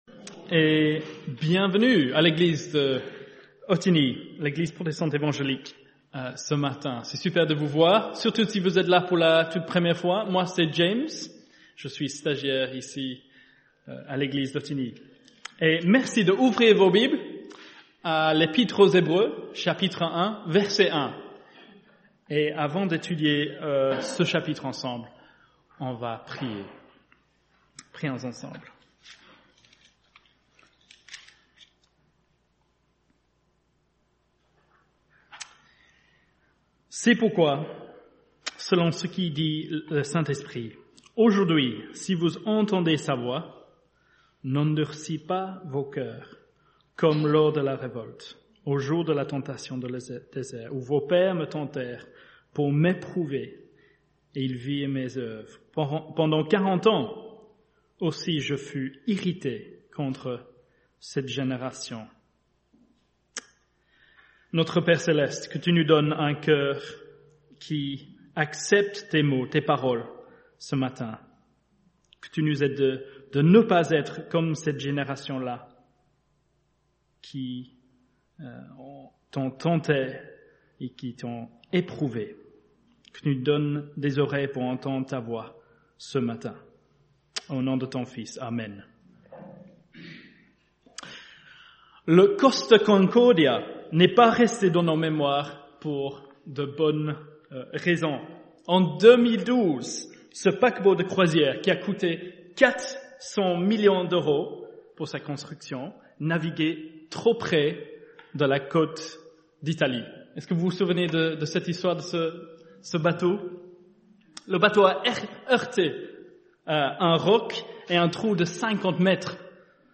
4 Type De Service: Dimanche matin « L’Eglise de Jésus-Christ doit se battre Etude sur 2 Pierre